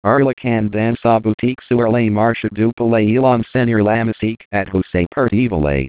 Les paroles ont été créés par synthèse vocale (Text-to-Speech Synthesis),